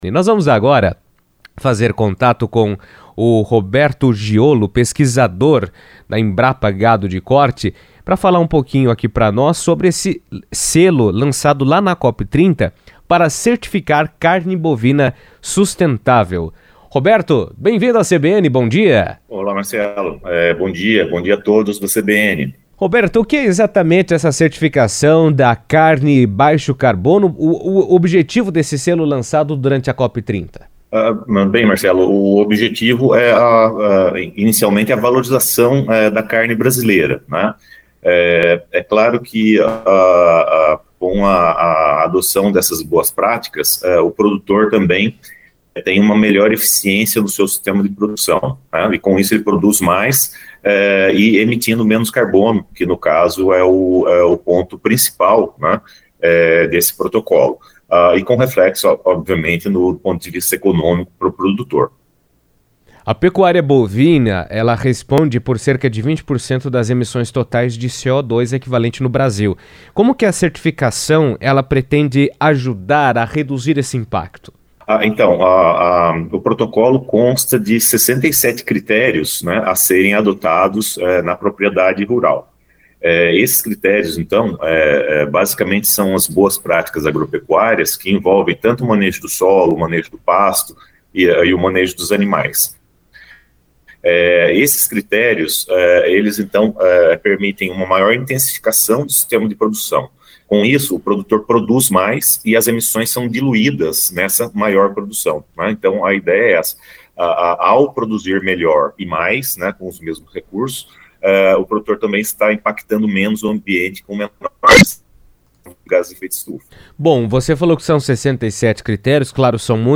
A Embrapa lançou durante a COP30 a certificação Carne Baixo Carbono (CBC), criada para reconhecer produtores rurais que adotam práticas capazes de reduzir as emissões de gases de efeito estufa na produção de carne bovina. A iniciativa busca incentivar uma produção mais sustentável, alinhada às metas ambientais nacionais e internacionais. Em entrevista à CBN Cascavel